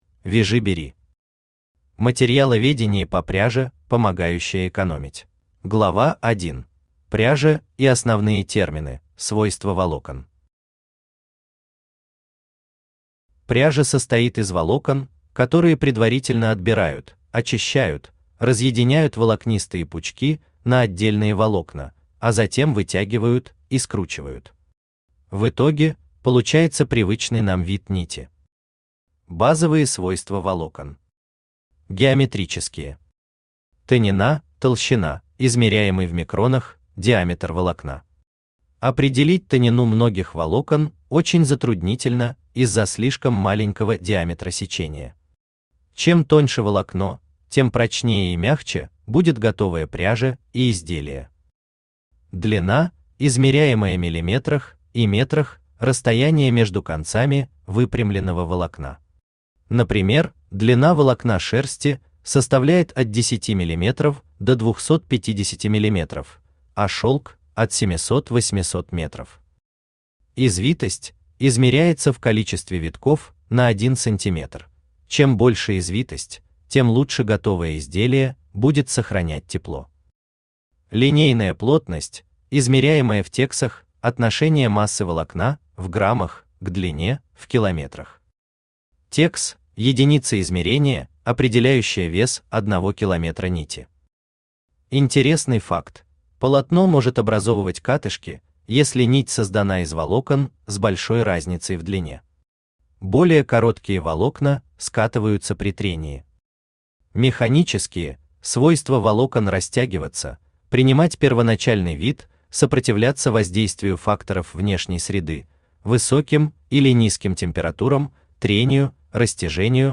Аудиокнига Материаловедение по пряже, помогающее экономить | Библиотека аудиокниг
Aудиокнига Материаловедение по пряже, помогающее экономить Автор Вяжи Бери Читает аудиокнигу Авточтец ЛитРес.